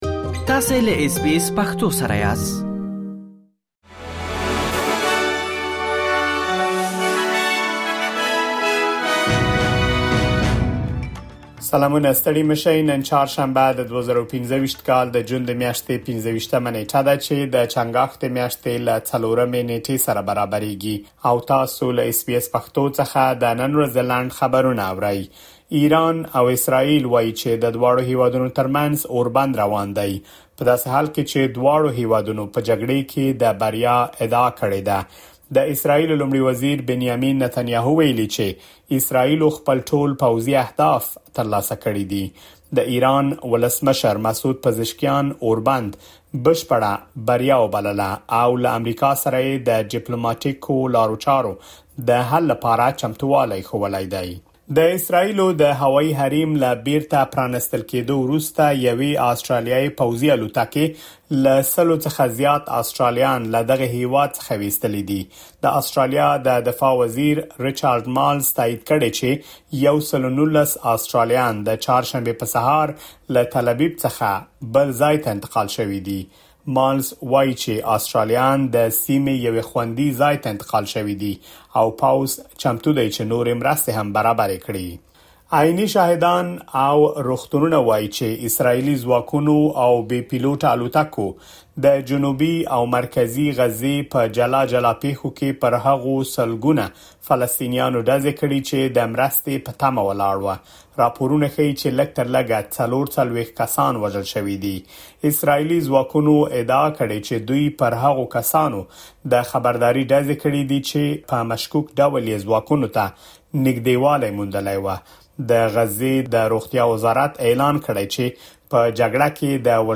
د اس بي اس پښتو د نن ورځې لنډ خبرونه | ۲۵ جون ۲۰۲۵
د اس بي اس پښتو د نن ورځې لنډ خبرونه دلته واورئ.